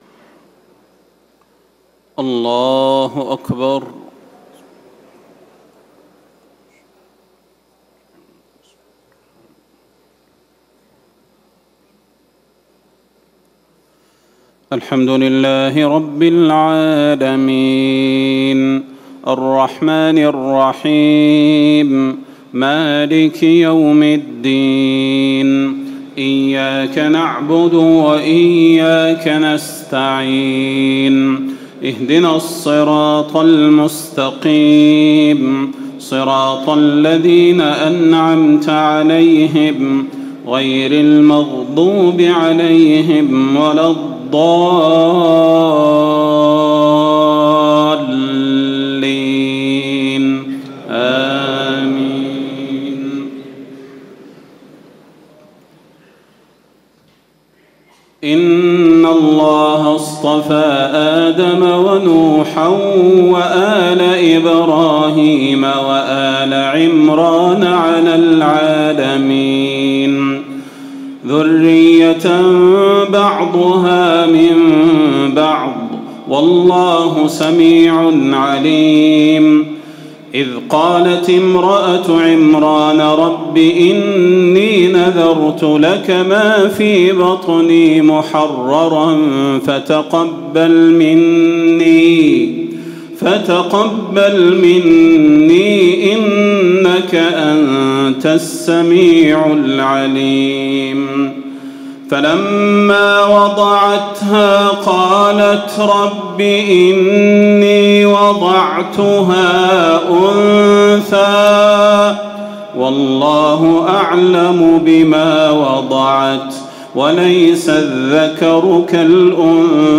تهجد ليلة 23 رمضان 1438هـ من سورة آل عمران (33-92) Tahajjud 23 st night Ramadan 1438H from Surah Aal-i-Imraan > تراويح الحرم النبوي عام 1438 🕌 > التراويح - تلاوات الحرمين